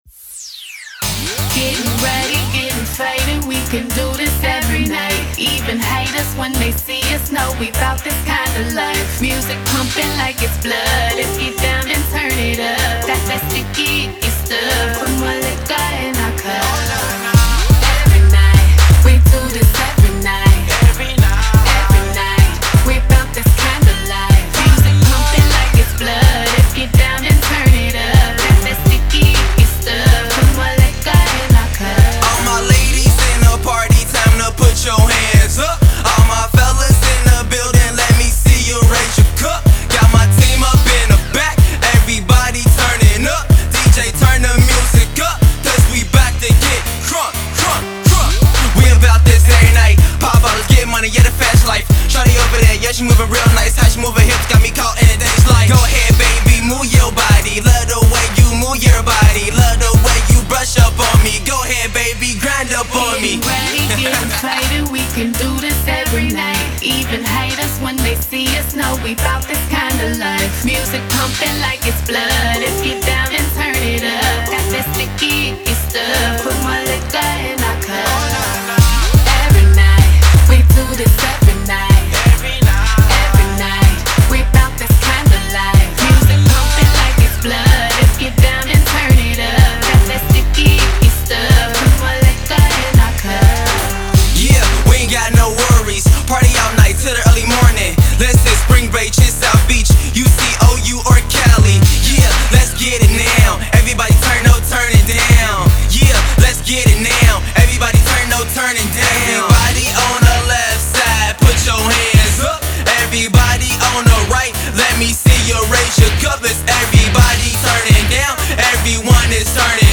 Dance
high energy cross over song